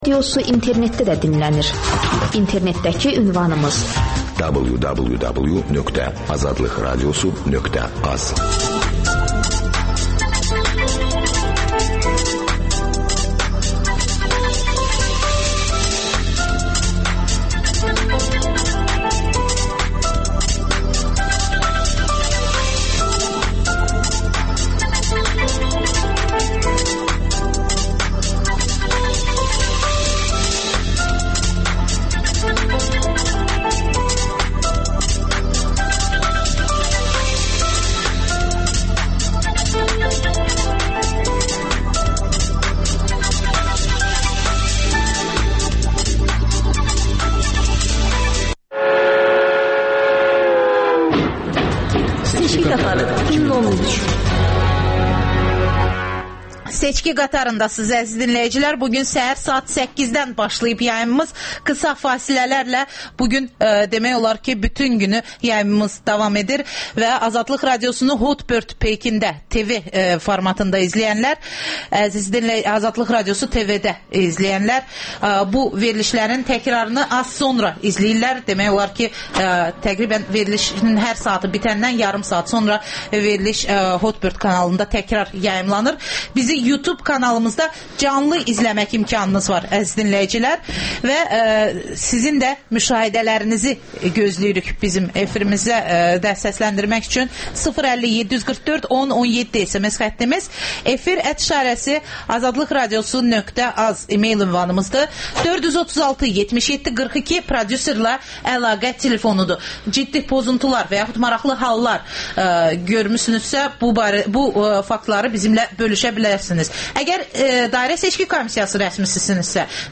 AzadlıqRadiosunun müxbirləri məntəqə-məntəqə dolaşıb səsvermənin gedişini xəbərləyirlər.